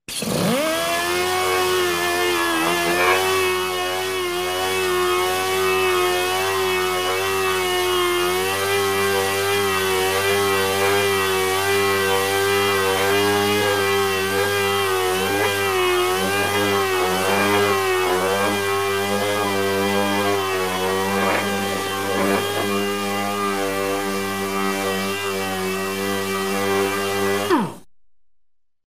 Звуки автосервиса
Шум пескоструйного аппарата при работе